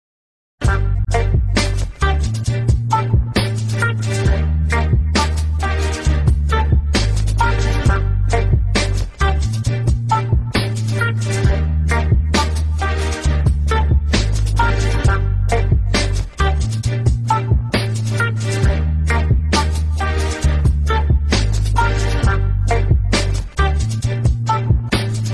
Nada dering best rap music
HipHop